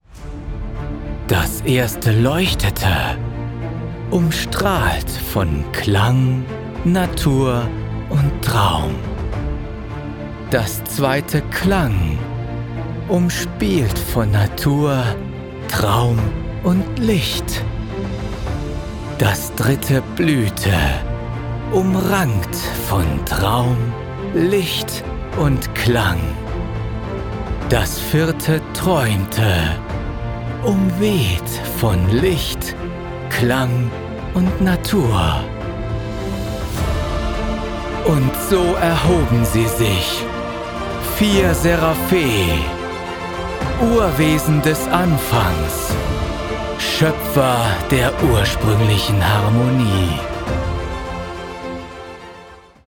Sprecher, Werbesprecher